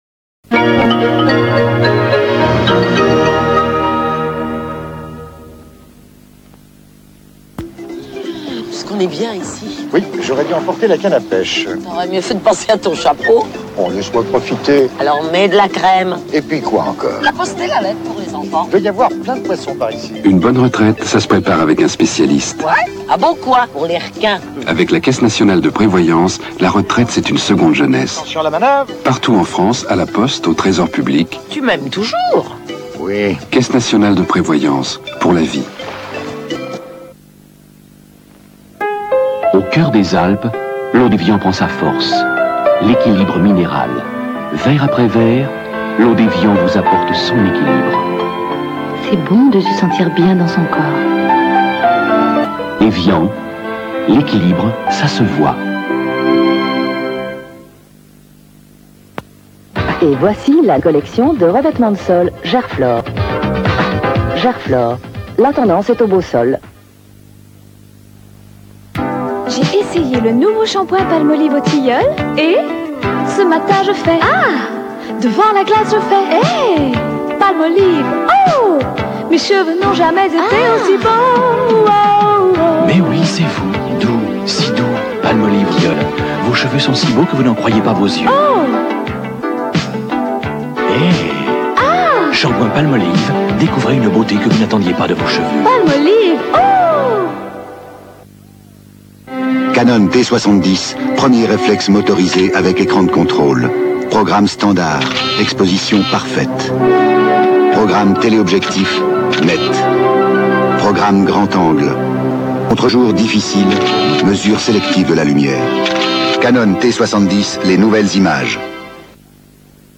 > 18/06/1985 | FR3 | PUBLICITE | 5'05" | REALmedia | 256Kb/s | 9.41Mo
C'est vrai que ça m'a aussi paru long, faut dire que les pubs (on devrait presque dire réclame ;) ) sont plus longues aussi... pour info c'est le spot qui suit un soir3...